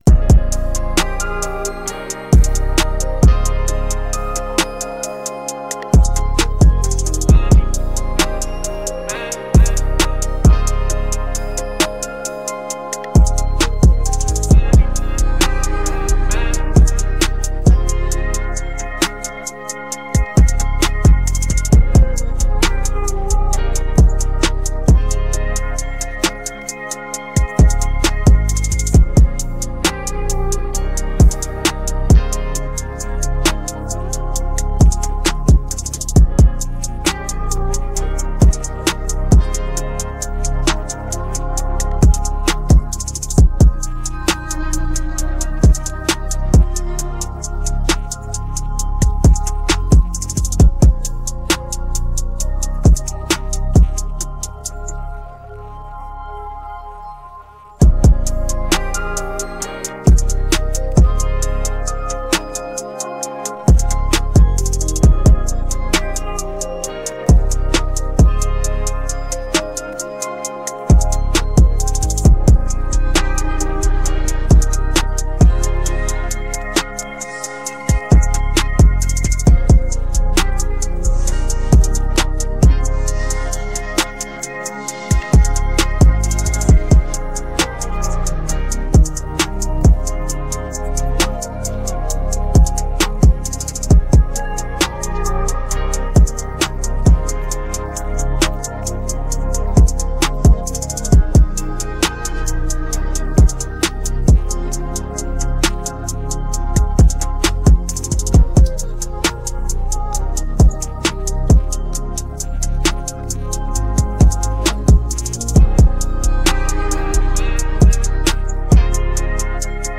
Afrobeats hiphop trap beats